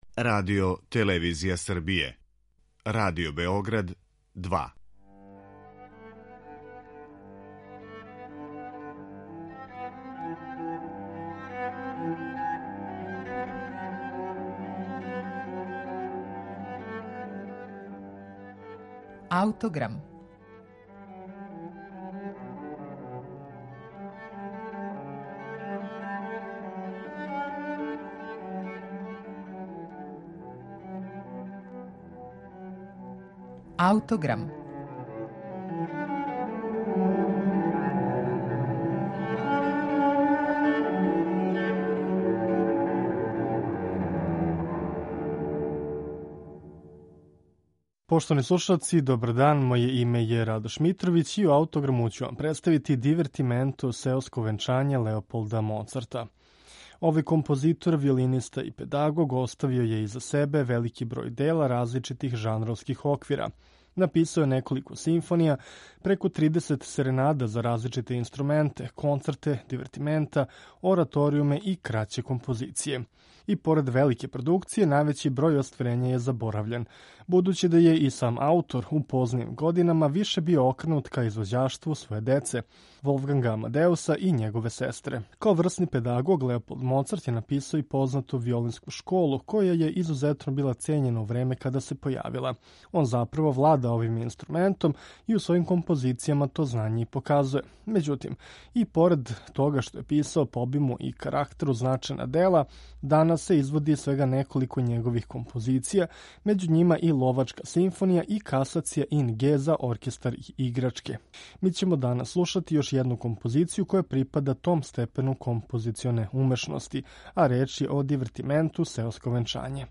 Иако је писао и сложенија и обимнија остварења, данас је, међу неколицином, остала на репертоарима једна лака композиција за оркестар занимљивог назива ‒ „Сеоска свадба". Уз мноштво необичних инструменталних боја, ово дело поседује карактер пун духа и хумора. Слушамо га у интерпретацији Амстердамског барокног ансамбла, под управом Тона Купмана.